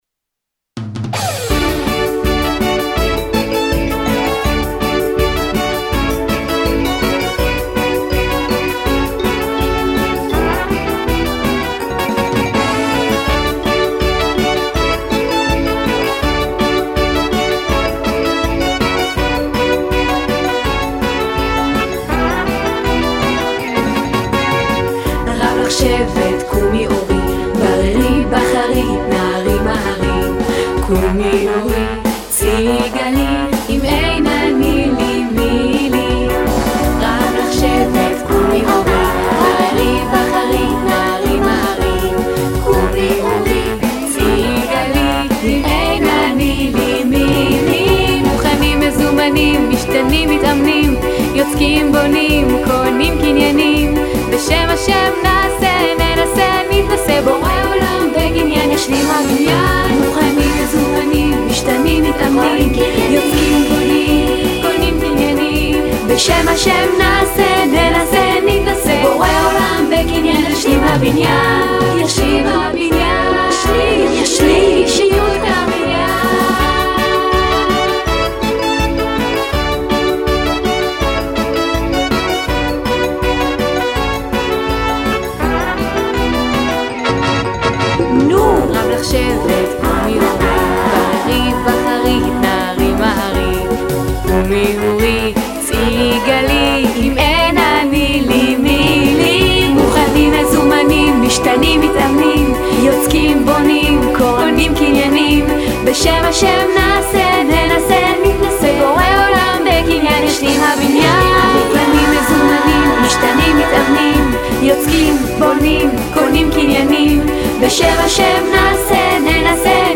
שירת נשים